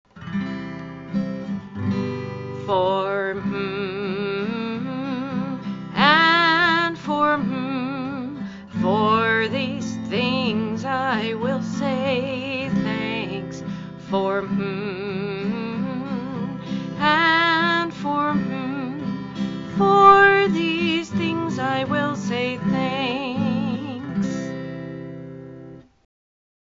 (tune: “Angels Watching Over Me” - traditional spiritual)